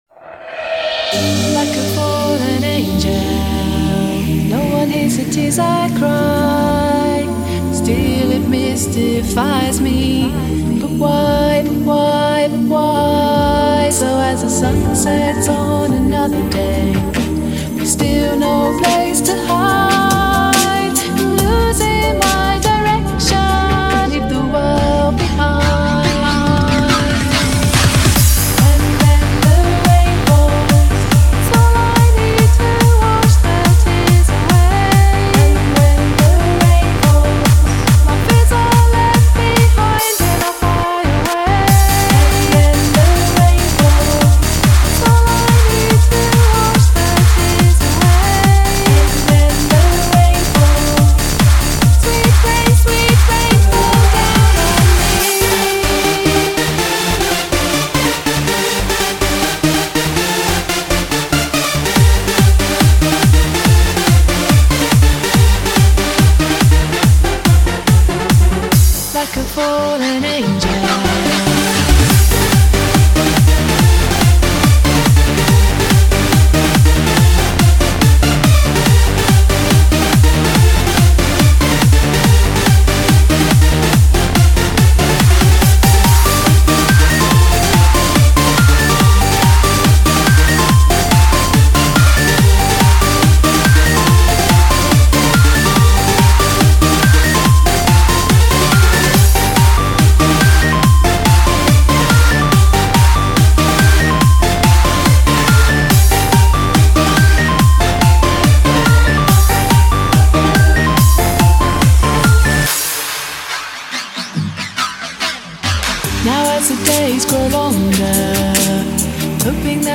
Назад в ¤Super / Club / Dance¤
Жанр:Новогодний/Позитивный/Club/Dance